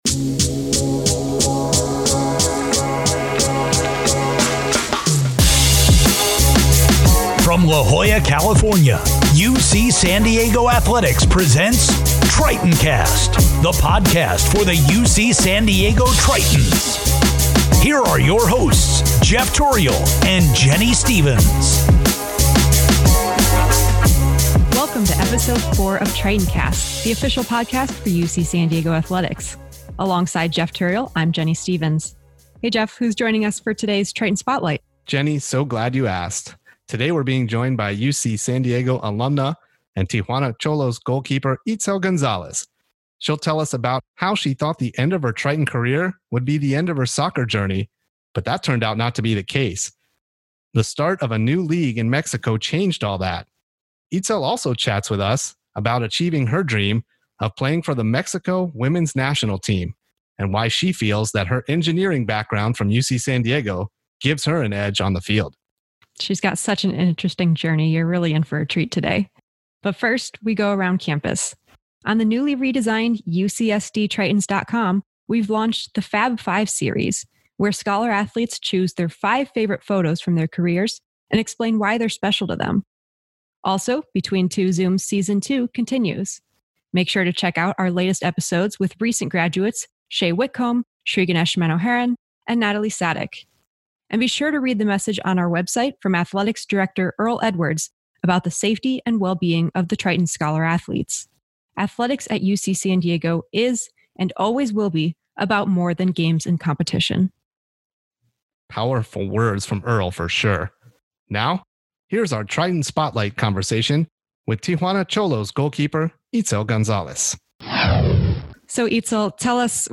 Find out in this week's conversation